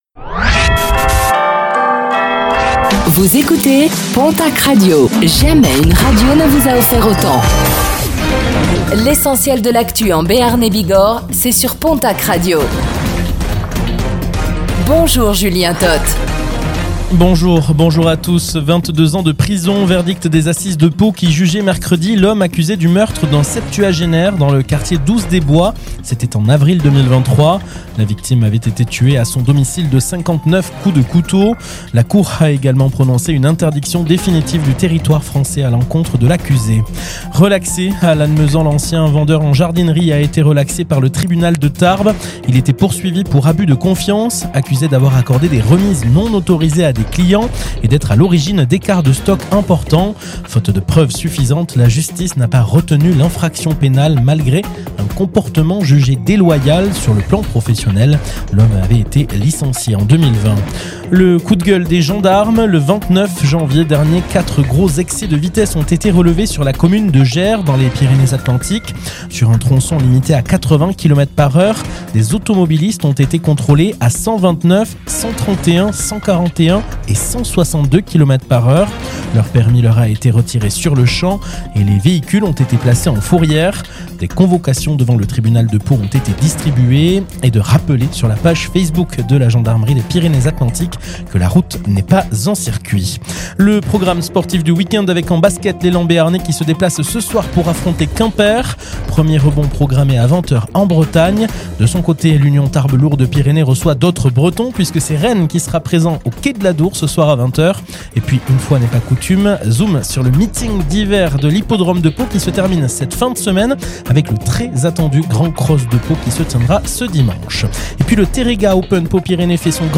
Réécoutez le flash d'information locale de ce vendredi 06 février 2026